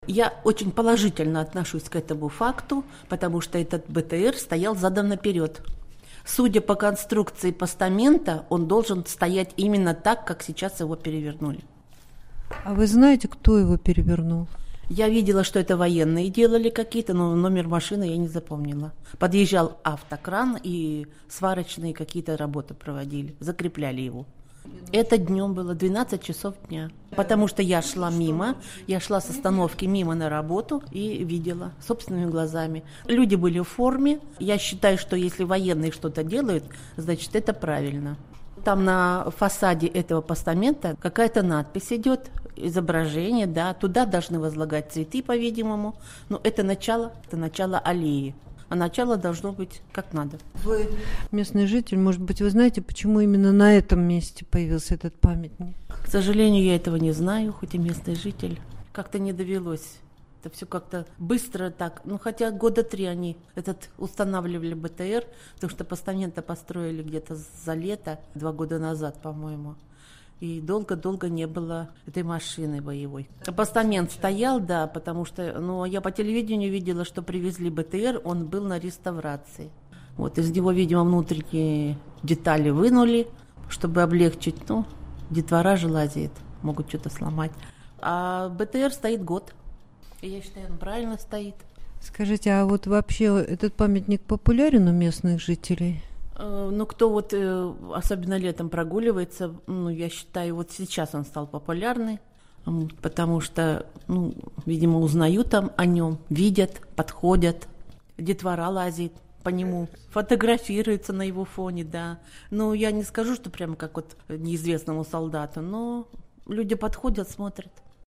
Жительница Новосибирска о развороте БМП на памятнике